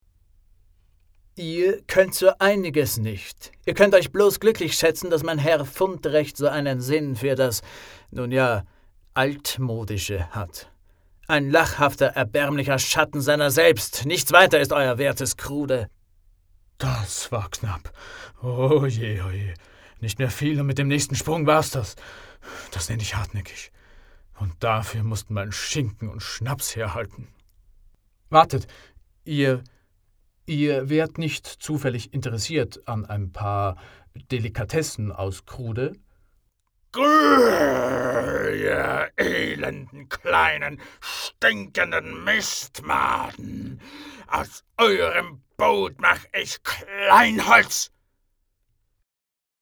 Wach, lebendig, resonierend, anpassungsfähig, dynamisch, gelassen
Sprechprobe: Sonstiges (Muttersprache):